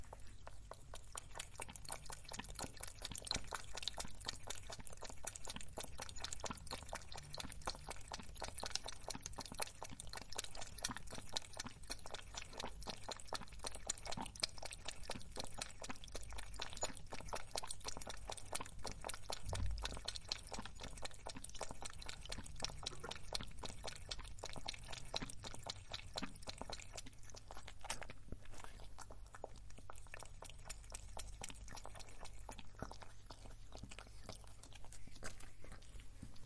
Звуки кошки, лакающей молоко: кошка пьет молоко языком